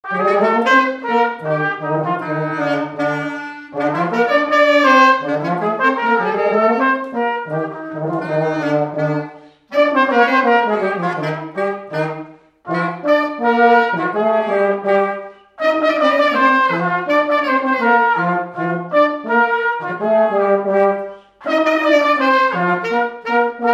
Scottish
Résumé instrumental
danse : scottish
circonstance : fiançaille, noce
Pièce musicale inédite